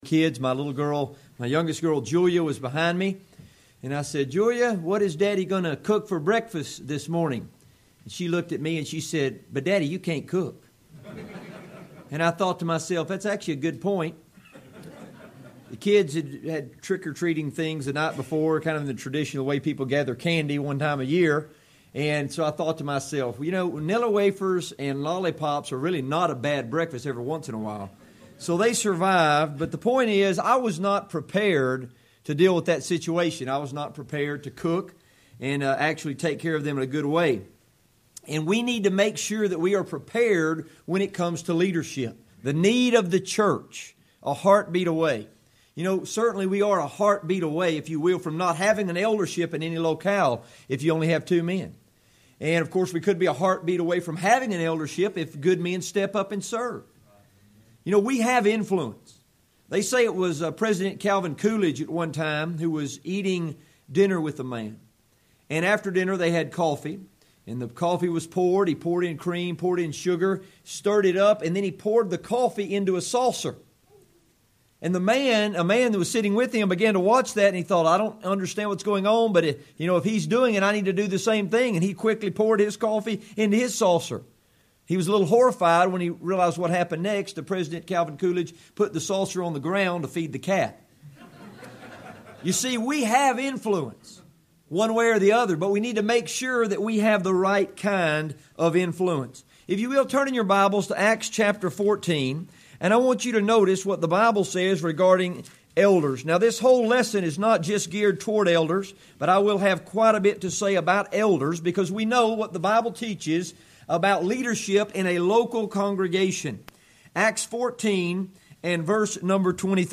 Make Me A Servant: A Workshop for Those Who Lead and Serve
lecture